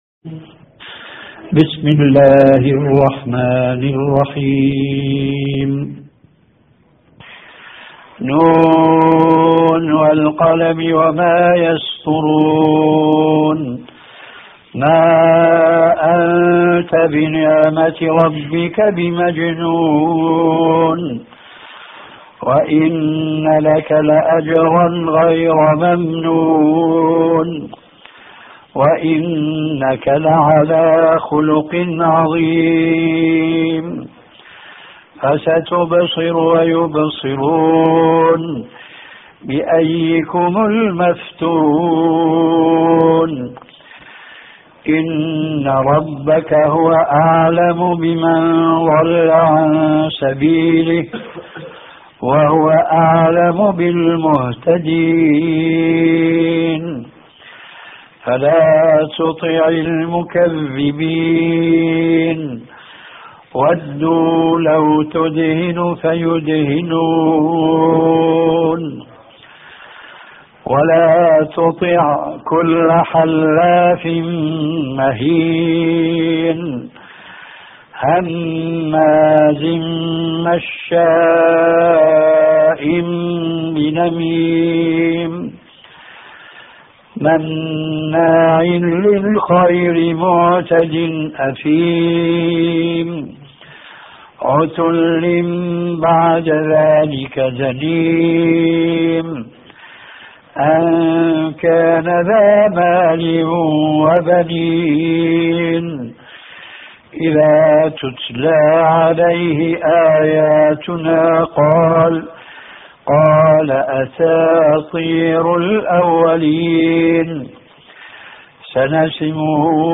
تلاوة من سورة القلم